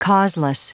Prononciation du mot causeless en anglais (fichier audio)
Prononciation du mot : causeless